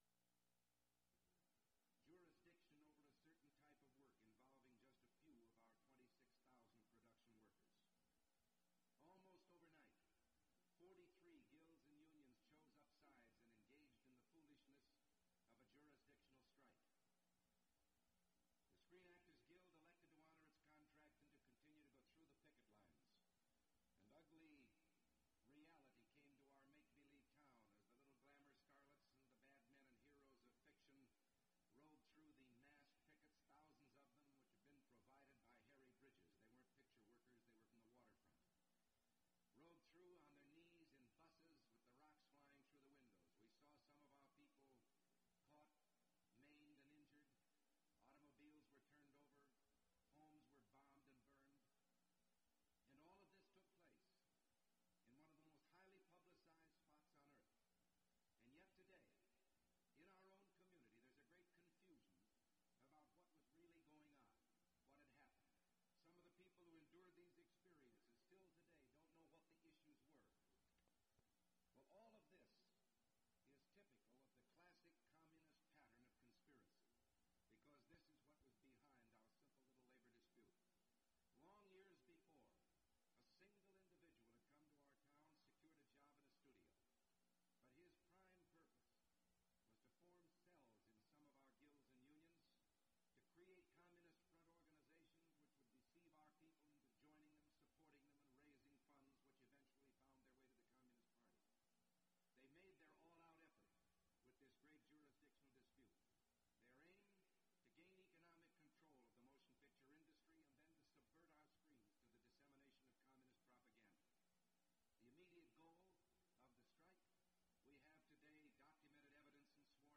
Ronald Reagan speech to Sioux City Chamber of Commerce, Iowa (in progress)
Reel to Reel Audio Format (CD copy).